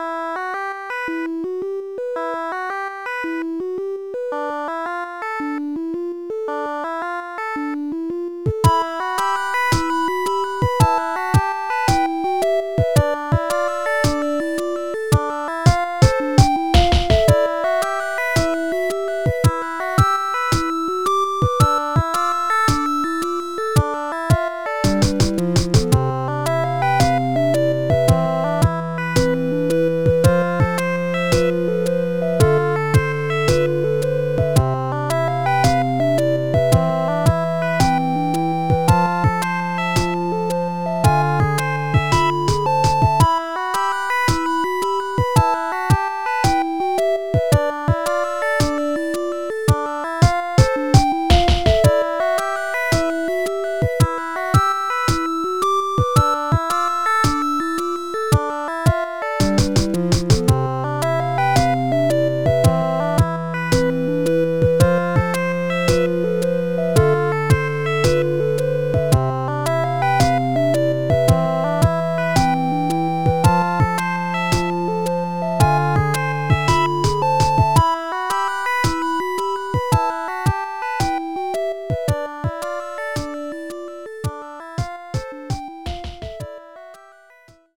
111bpm